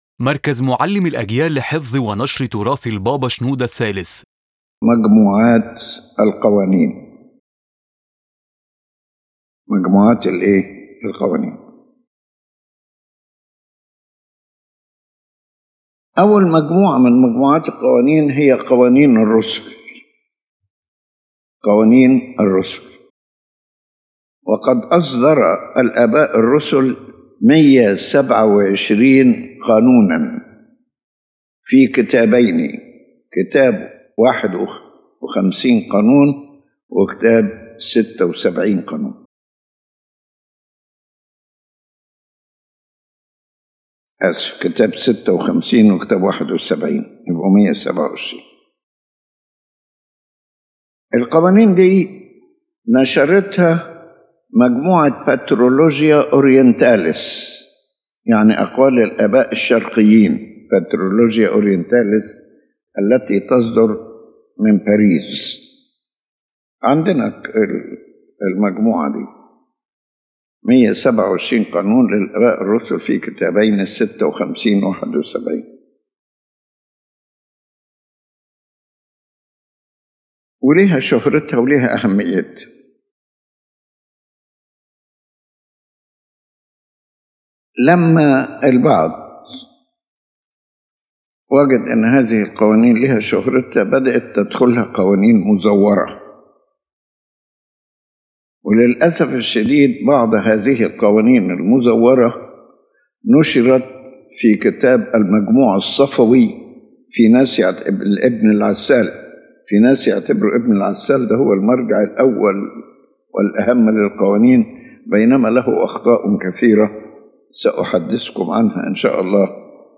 His Holiness Pope Shenouda III presents in this lecture the classification of collections of ecclesiastical laws and their sources, and he shows the importance of each collection and the problems related to some forged references.